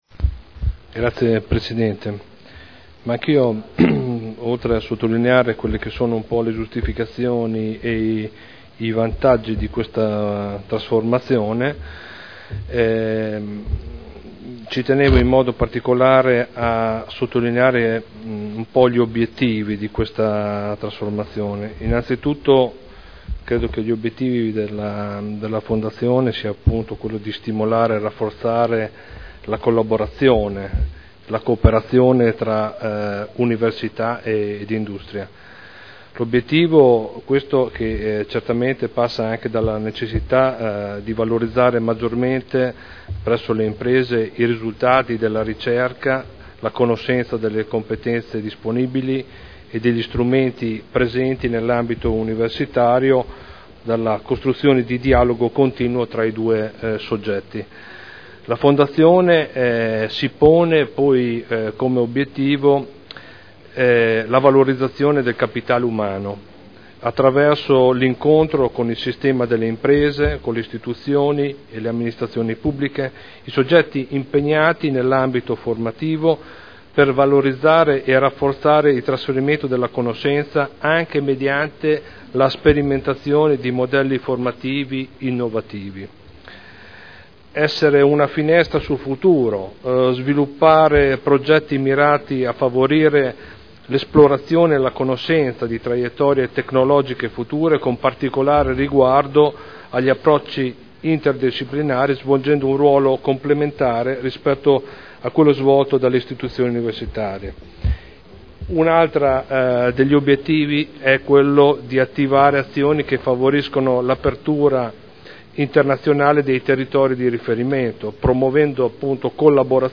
Seduta del 22/12/2011. Dibattito su proposta di deliberazione.